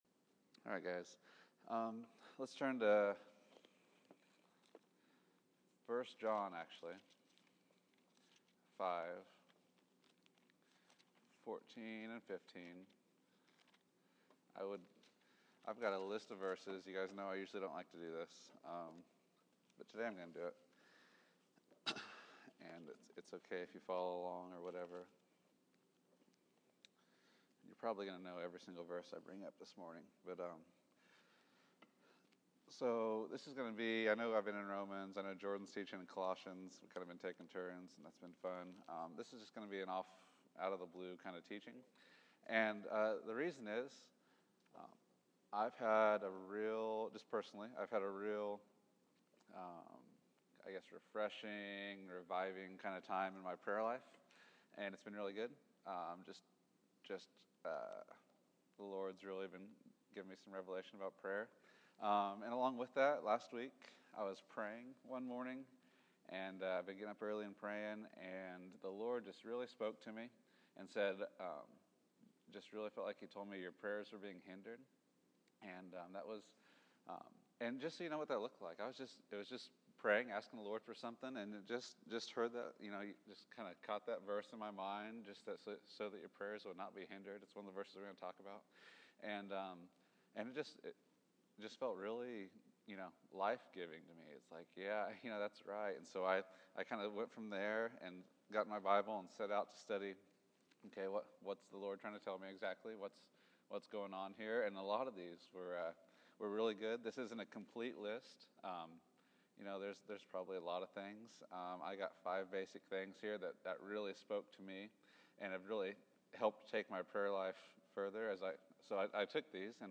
Hindered Prayers March 29, 2015 Category: Sunday School | Location: El Dorado Back to the Resource Library Five actions that the Bible says causes our prayers to be hindered.